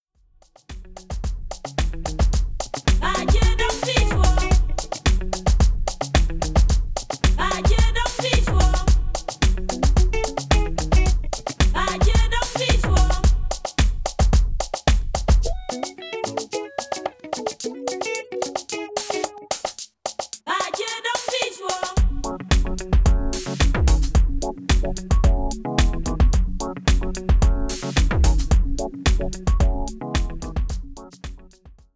ジャンル(スタイル) DEEP HOUSE / AFRO HOUSE